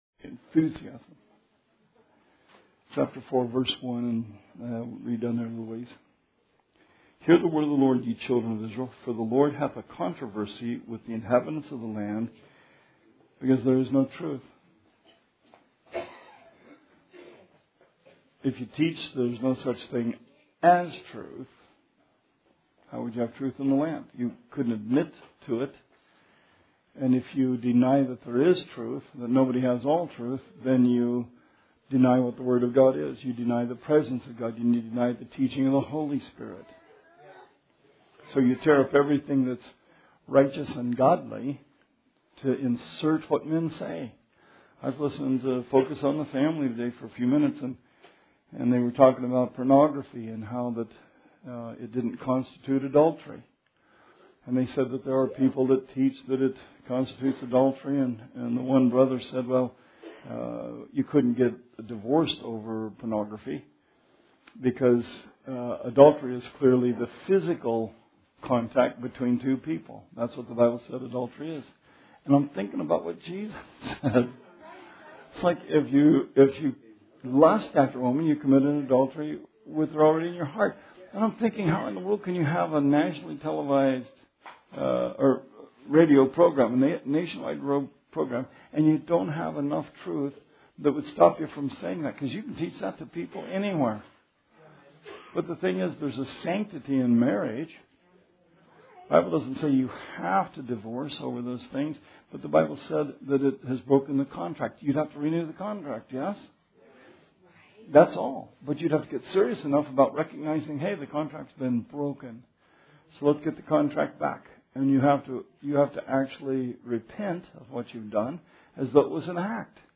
Bible Study 5/25/16